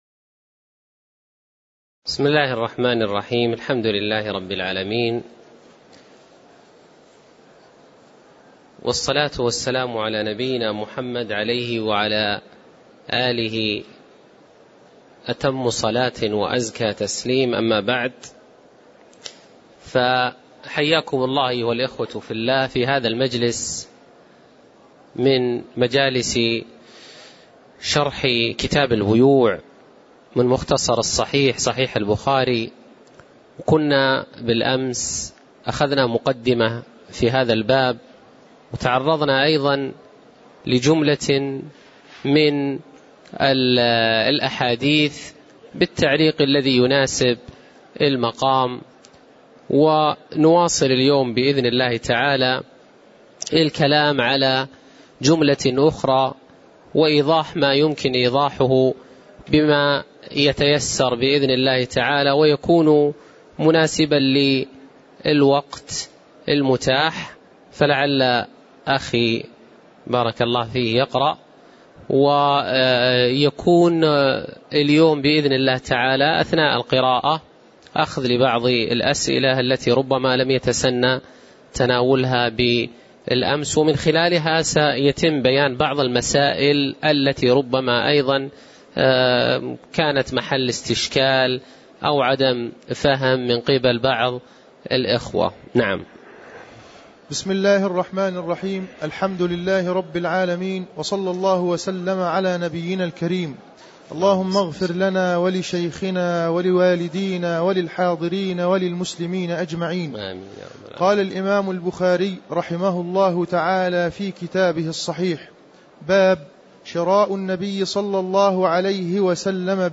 تاريخ النشر ٤ جمادى الأولى ١٤٣٨ هـ المكان: المسجد النبوي الشيخ